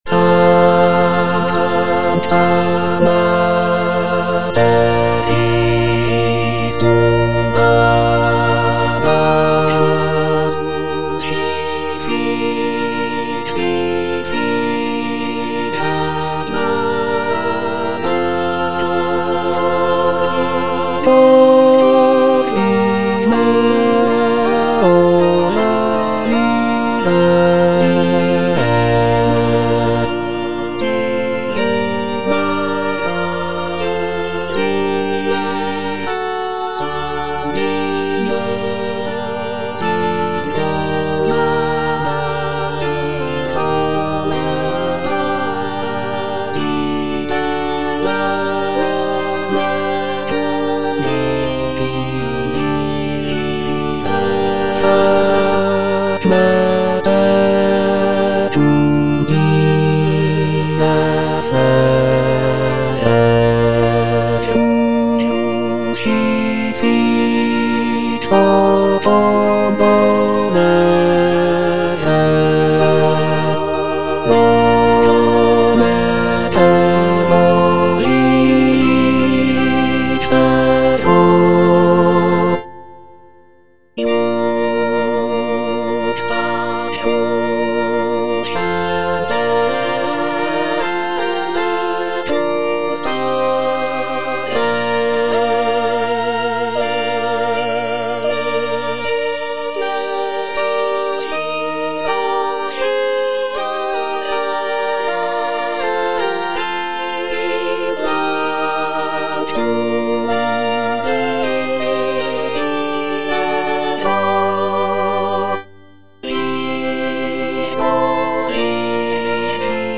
(bass II) (Emphasised voice and other voices) Ads stop
sacred choral work